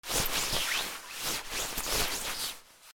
布ずれ 激しく
/ J｜フォーリー(布ずれ・動作) / J-05 ｜布ずれ
『シュルシュル』